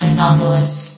CitadelStationBot df15bbe0f0 [MIRROR] New & Fixed AI VOX Sound Files ( #6003 ) ...
anomalous.ogg